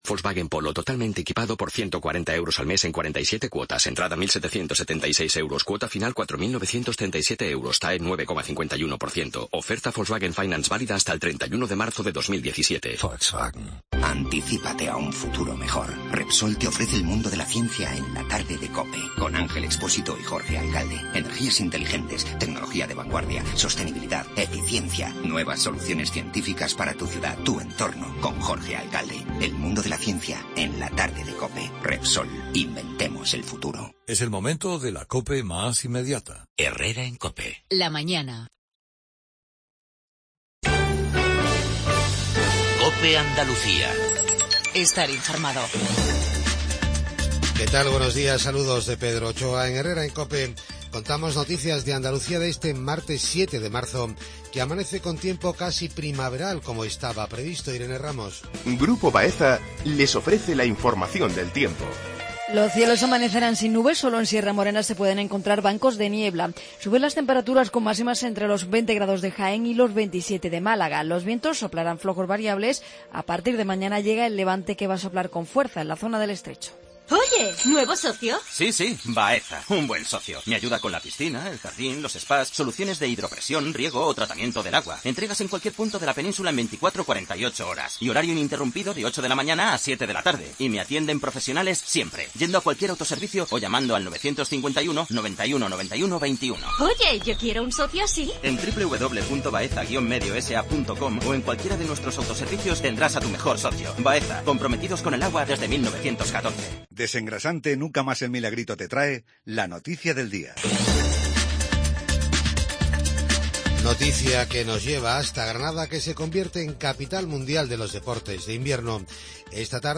INFORMATIVO REGIONAL/LOCAL MATINAL